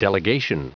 Prononciation du mot delegation en anglais (fichier audio)
Prononciation du mot : delegation